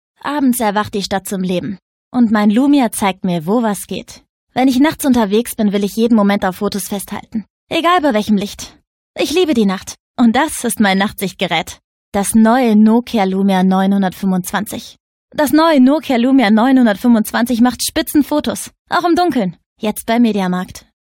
Werbung Nokia Deutsch